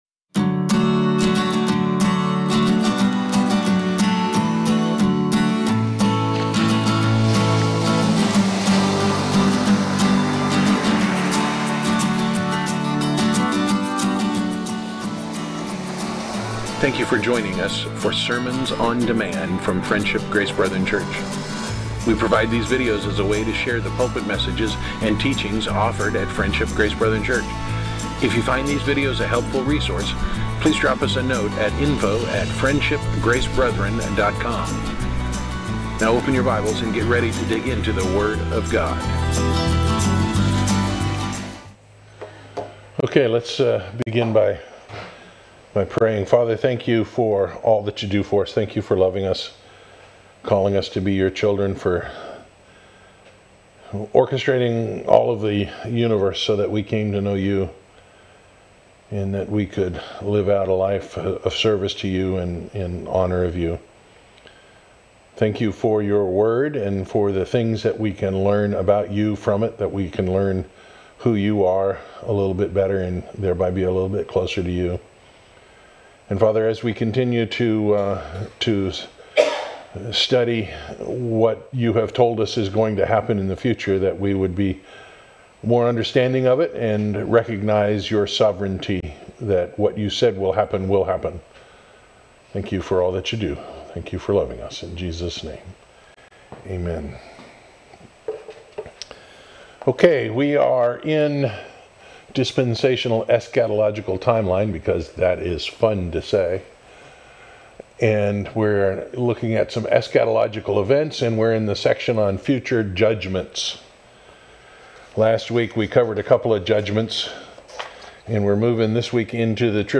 Series: Dispensational Eschatology, Sunday School